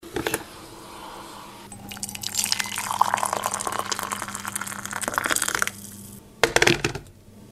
SFX从壶里倒水(Pouring water from the k音效下载
SFX音效